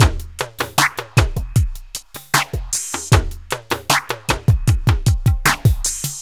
Downtempo 22.wav